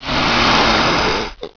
hiss1.wav